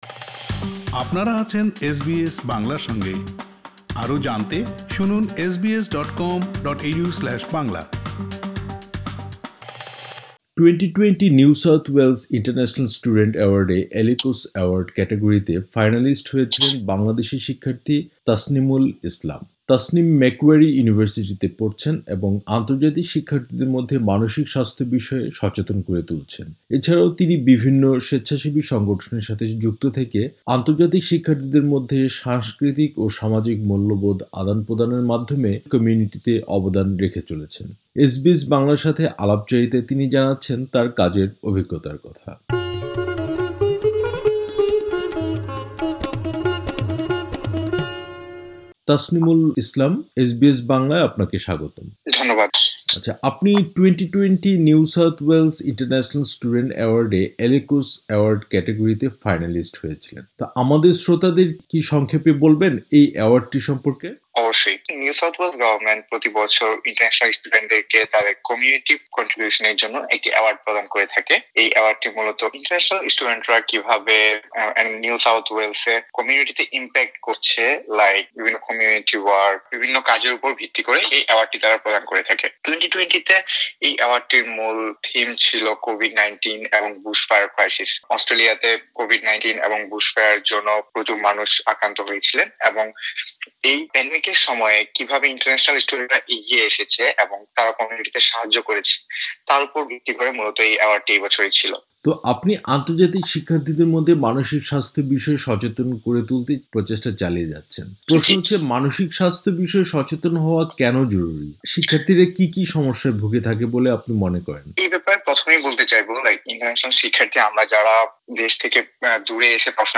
এসবিএস বাংলার সাথে আলাপচারিতায় তিনি জানাচ্ছেন তার কাজের অভিজ্ঞতার কথা।